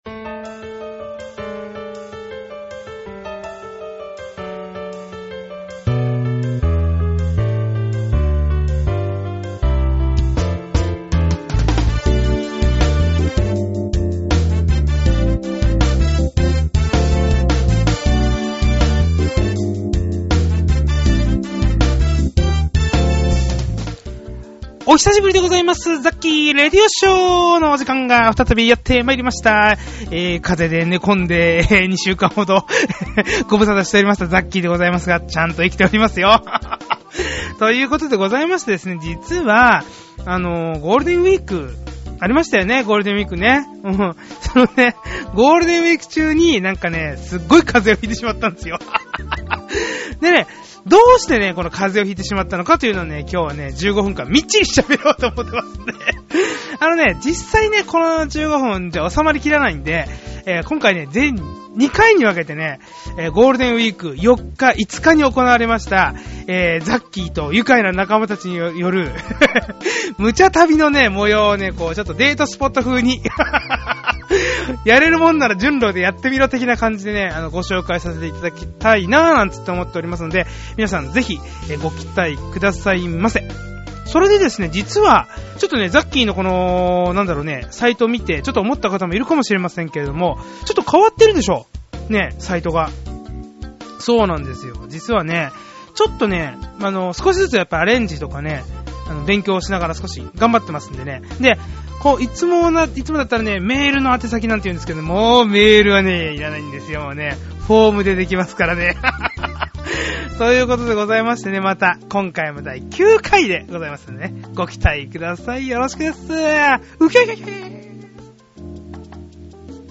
兎に角鼻声ですみません。